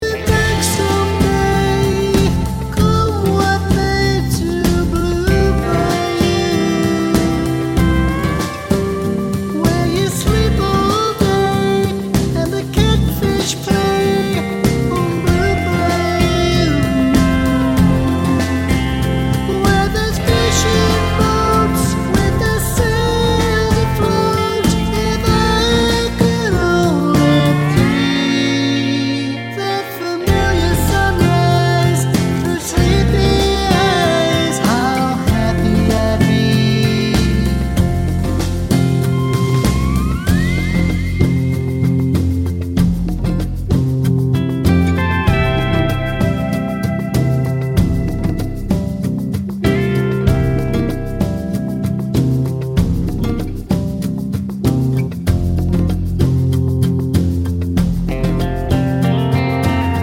One Semitone Higher Country (Male) 3:57 Buy £1.50